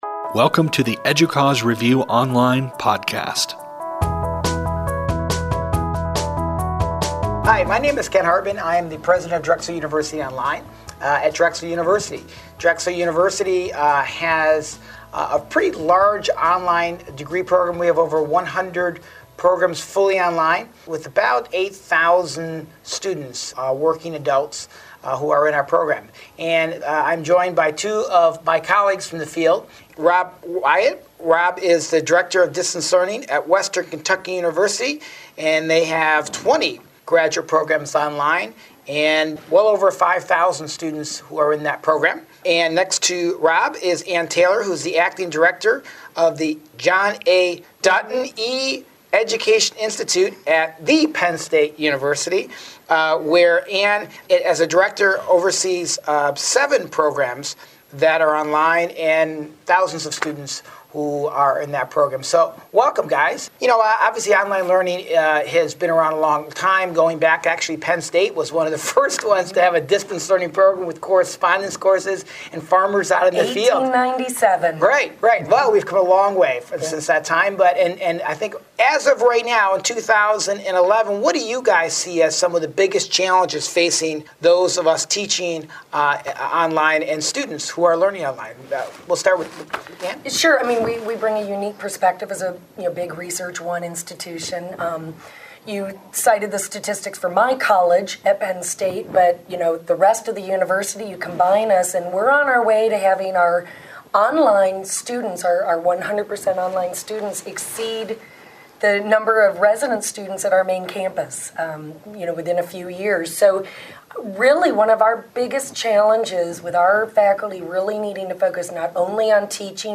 In this podcast, three directors of online education programs talk about designing for online courses and their efforts toward quality and retention.